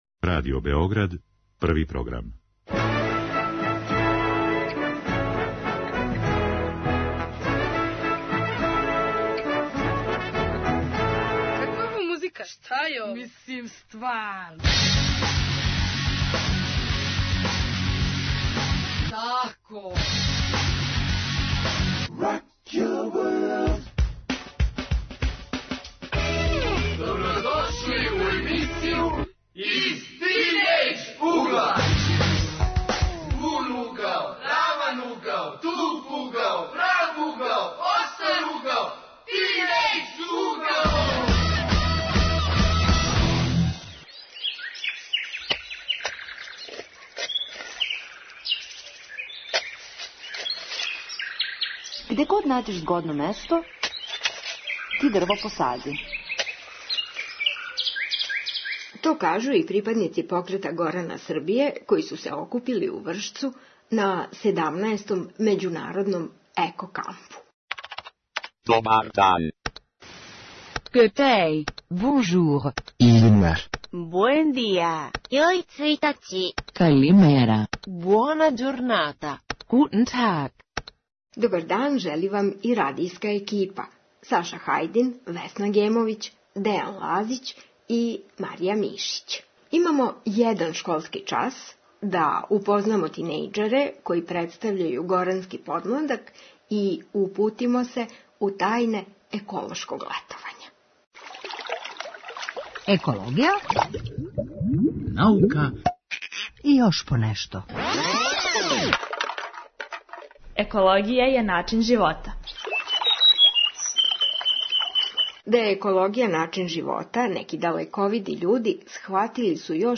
Покрет Горана Србије о овог лета организације међународни еколошки камп у Вршцу. Тинејџерски део екипе поделиће са нама знања стечена на терену у Вршцу и околини.